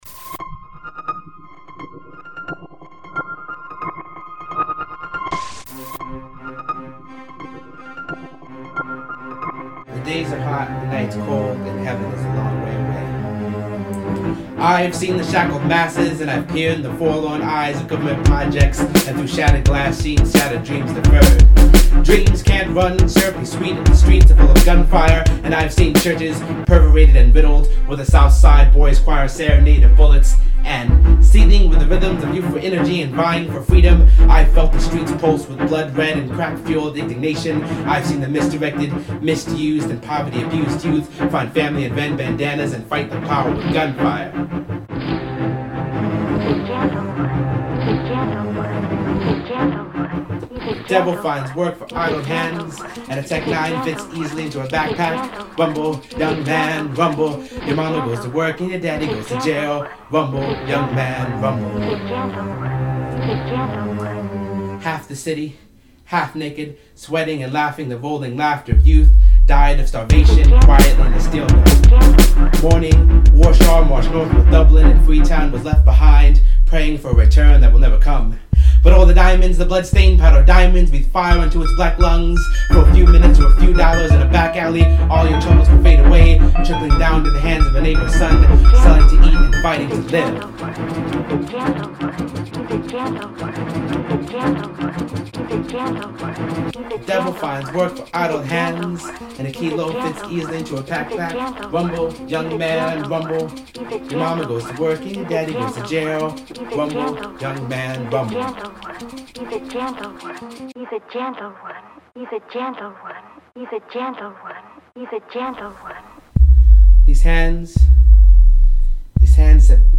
I’ve wanted to set this poem to music since the moment I wrote it.
shattered-dream-deffered-to-music.mp3